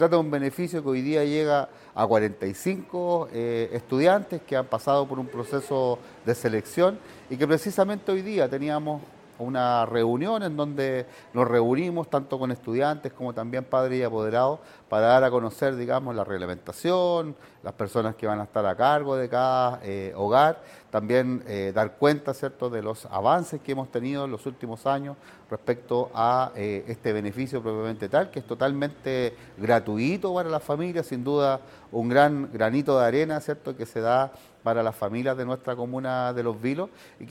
A través de una reunión de carácter informativa se dio a conocer a los 45 estudiantes que resultaron beneficiados por la Beca de Residencia Estudiantil 2025, entregándoles detalles a ellos, junto a sus familias, de los procedimientos internos de cada uno de los recintos para su correcto funcionamiento. Así lo comentó el alcalde de Los Vilos, Christian Gross, quién estuvo presente en la actividad: